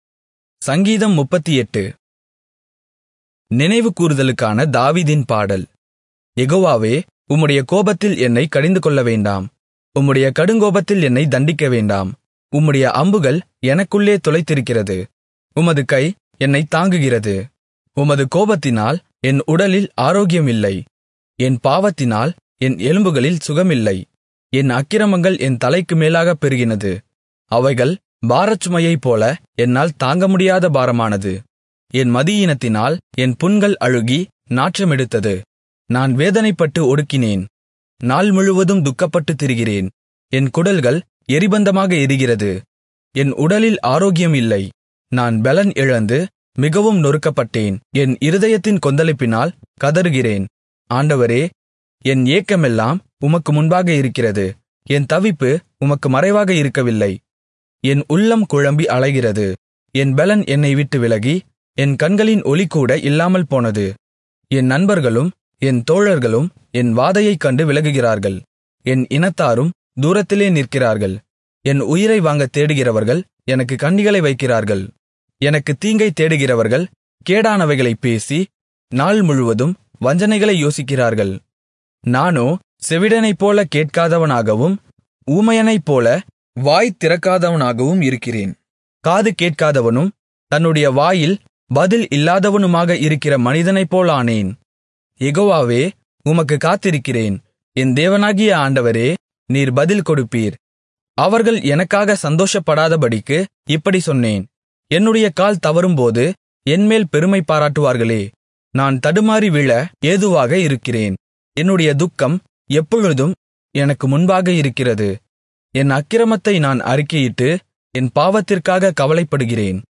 Tamil Audio Bible - Psalms 3 in Irvta bible version